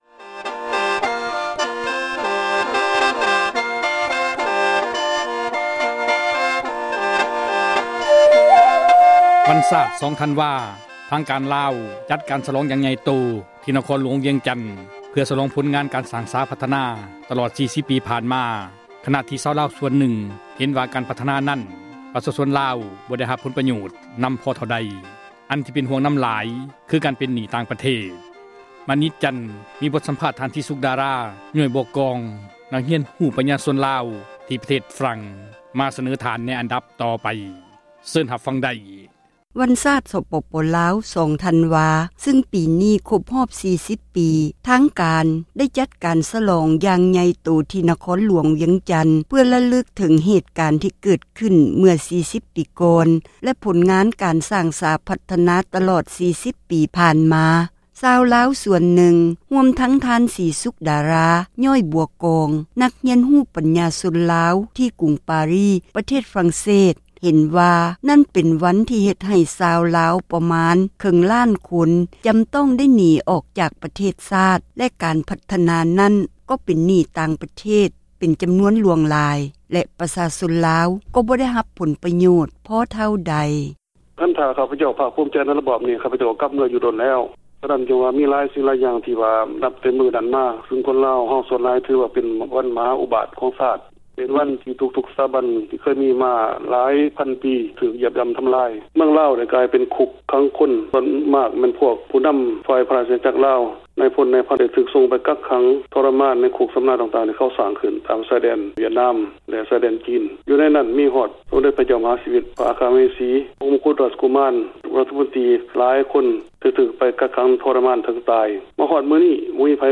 ສັມພາດ